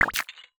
UIClick_Bubbles Splash Chains Metal 01.wav